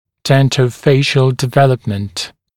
[ˌdentə(u)’feɪʃ(ə)l dɪ’veləpmənt][ˌдэнто(у)’фэйш(э)л ди’вэлэпмэнт]зуболицевое развитие